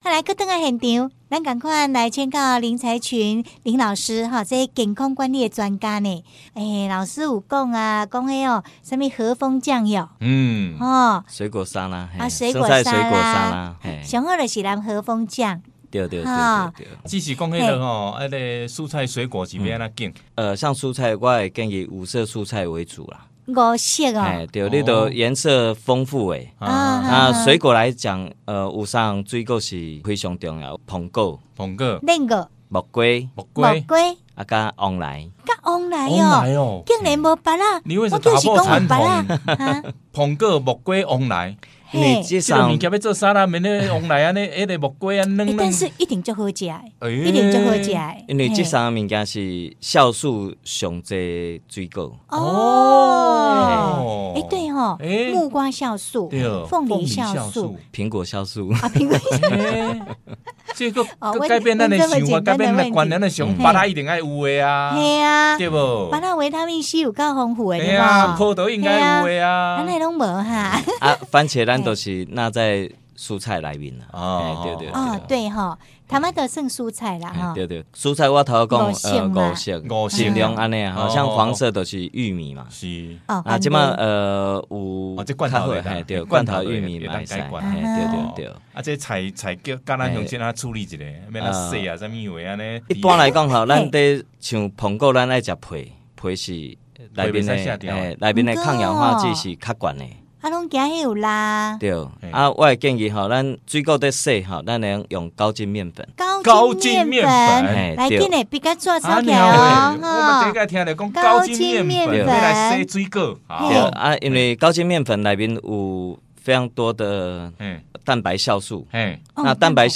擷取部份專訪內容: